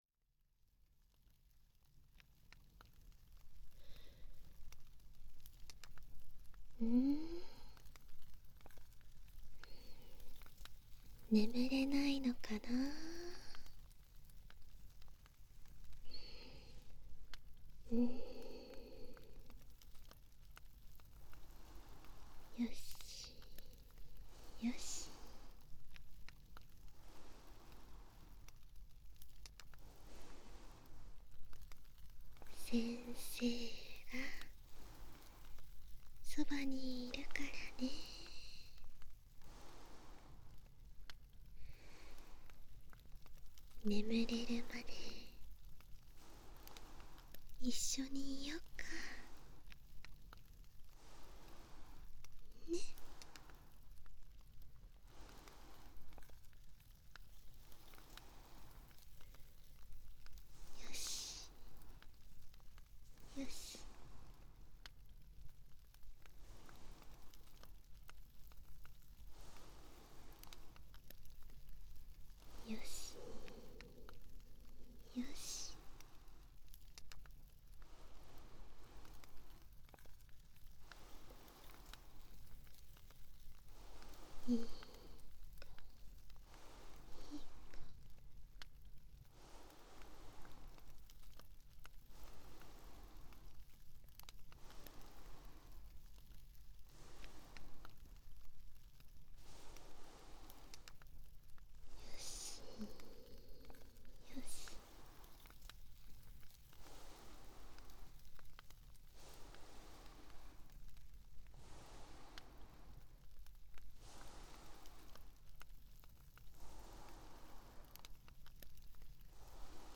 掏耳 环绕音 ASMR